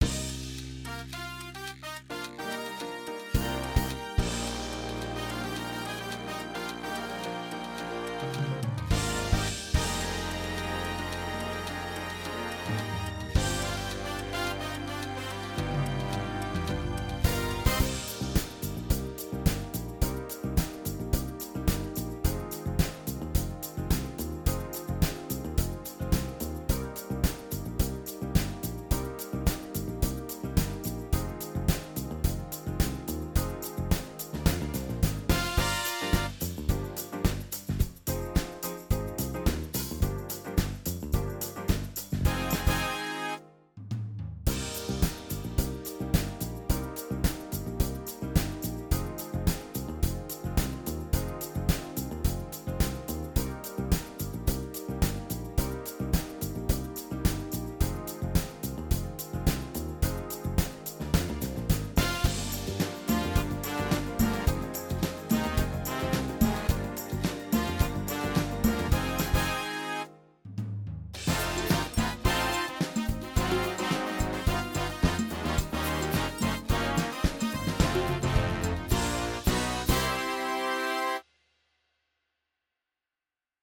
4 styles demo
Drums from Toontrack Superior Drummer 2
Percussions from Musyng Kite GM.sfpack
Accordion from Accordions Library for Kontakt. All the rest from sampled Motif XF.